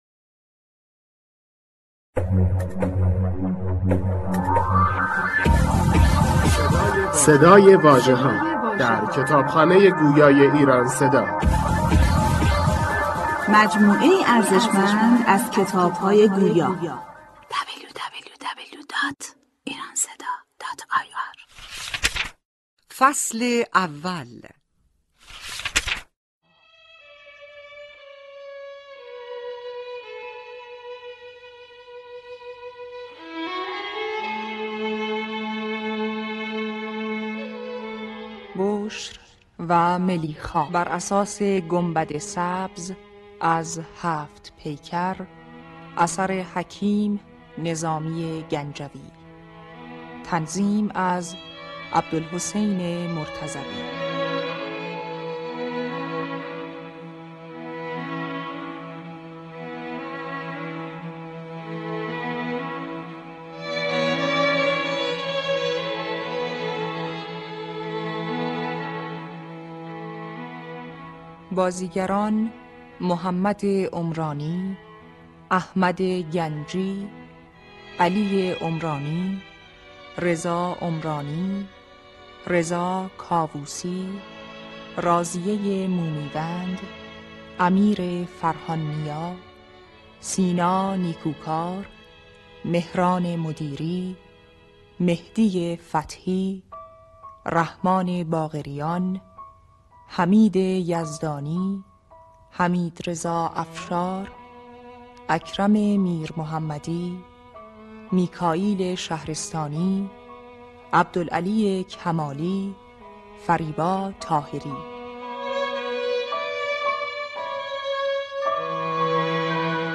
قالب: نمایشی
کتاب گویا بُشر و ملیخا | نمایشی شنیدنی از هفت پیکر نظامی با صدای بازیگران مطرح